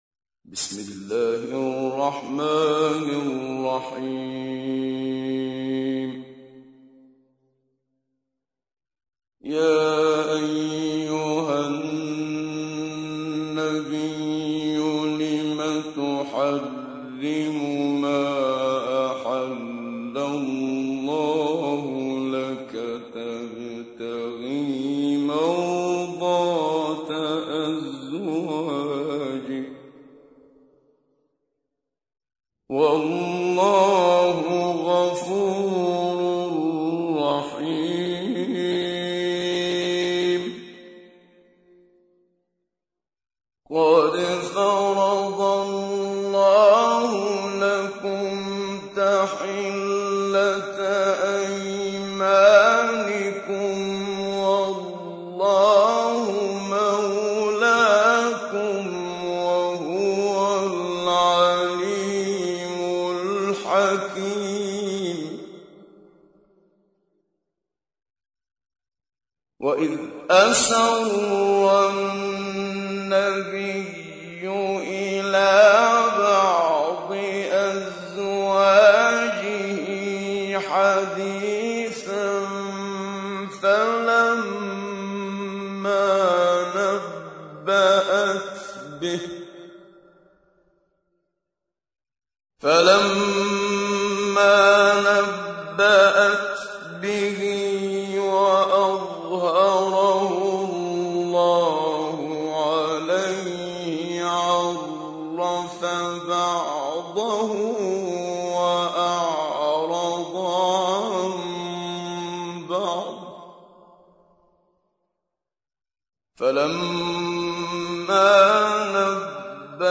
تحميل : 66. سورة التحريم / القارئ محمد صديق المنشاوي / القرآن الكريم / موقع يا حسين